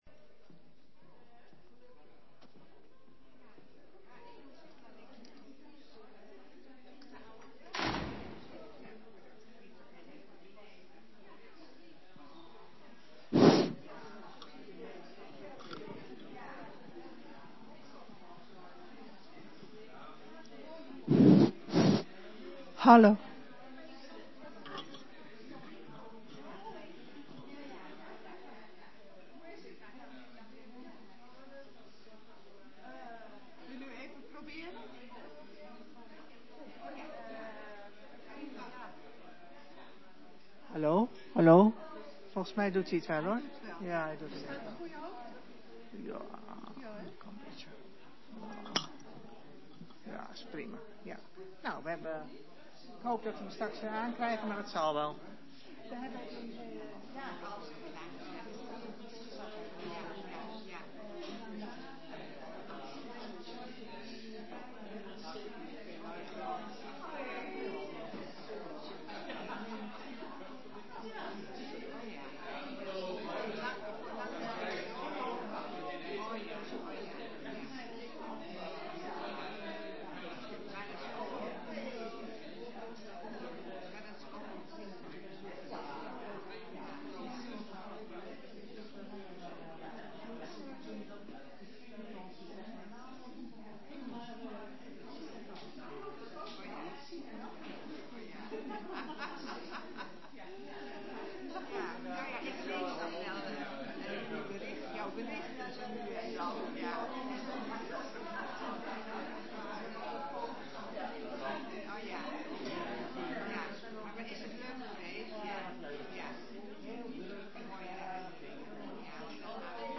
Tijdens een Passieviering in de vooravond van de Stille Week – zondag 13 april – brengt interkerkelijk koor To You uit Epe het Passie-oratorium “Harvest of Sorrows” (“Oogst van verdriet”) ten gehore in de Ontmoetingskerk in Heerde.